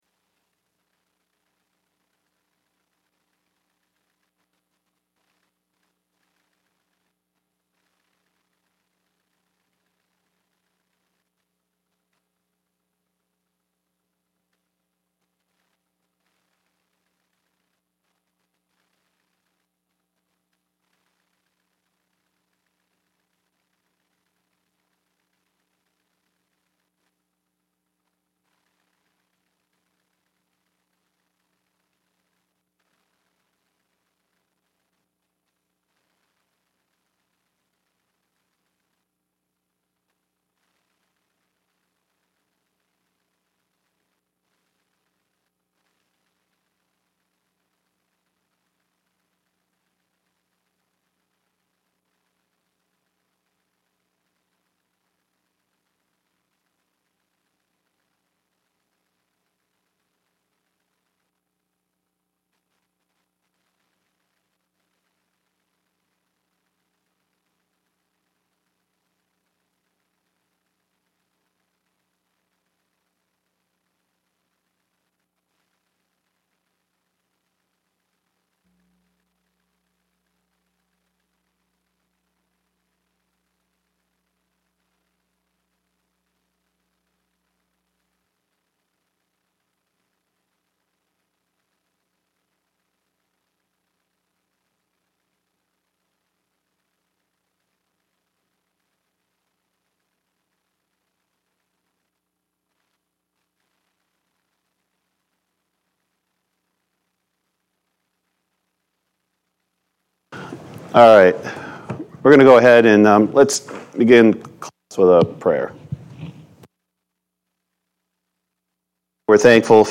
2. Singing Class
Acapella Singing « 1.